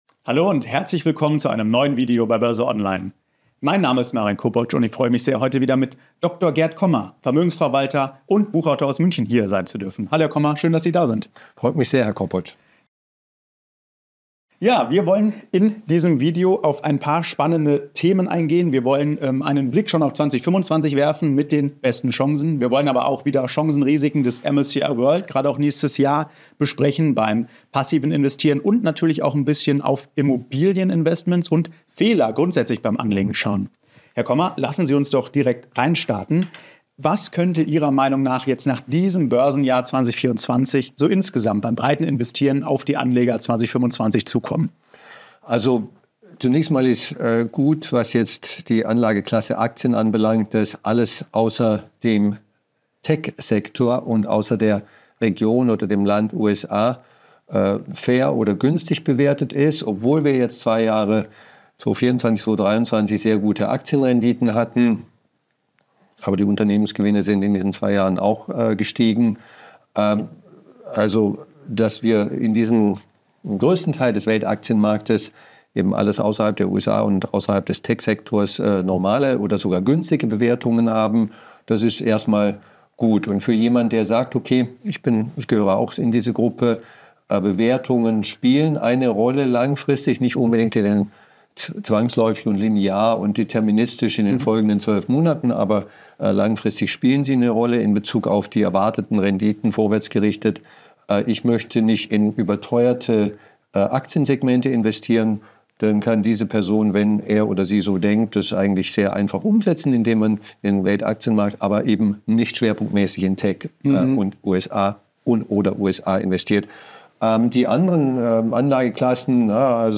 Sollten Sie jetzt keine Immobilien kaufen? BÖRSE ONLINE im Talk mit Vermögensverwalter Gerd Kommer ~ BÖRSE ONLINE Podcast